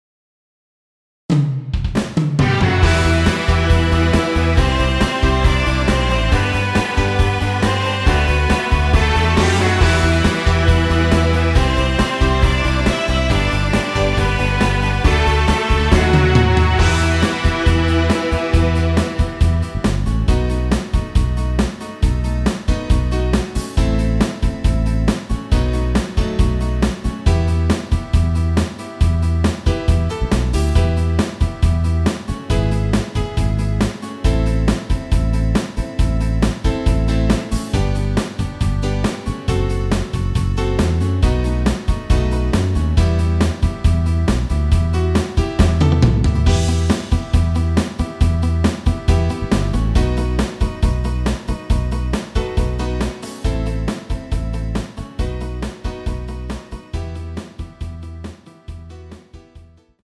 원키에서(+2)올린 MR입니다.
Em
앞부분30초, 뒷부분30초씩 편집해서 올려 드리고 있습니다.
중간에 음이 끈어지고 다시 나오는 이유는